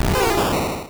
Cri de Bulbizarre dans Pokémon Rouge et Bleu.